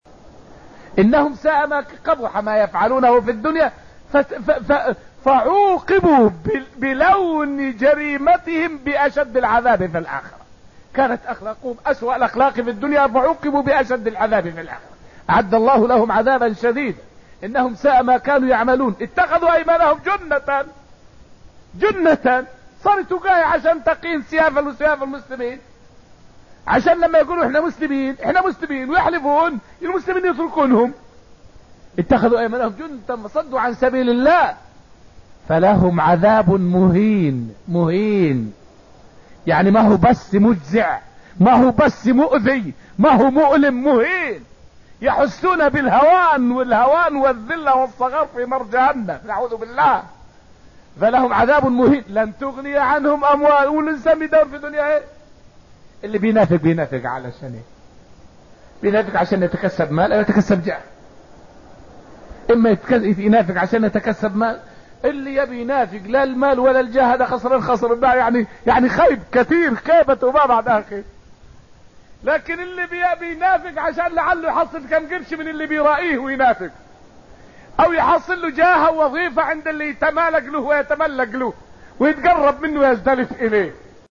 فائدة من الدرس العاشر من دروس تفسير سورة المجادلة والتي ألقيت في المسجد النبوي الشريف حول معنى قوله {إنهم ساء ما كانوا يعملون اتخذوا أيمانهم جنة}.